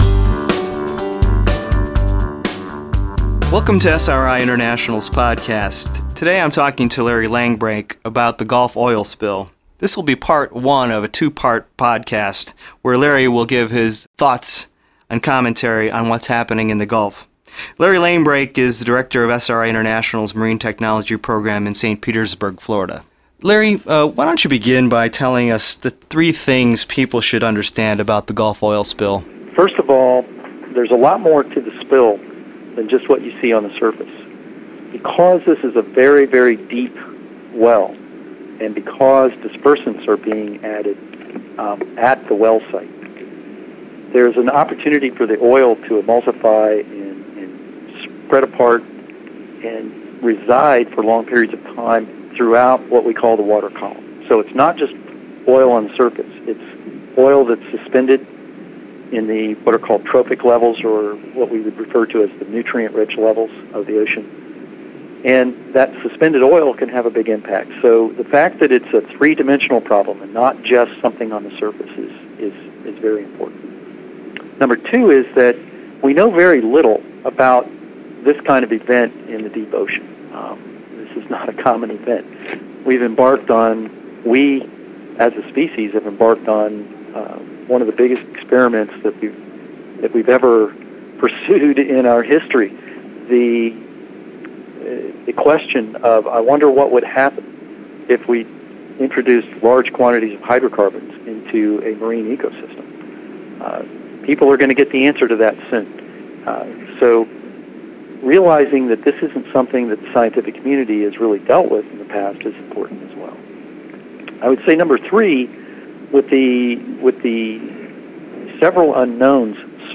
The podcast was recording and engineered at 2Bruce Studio in Asheville, NC.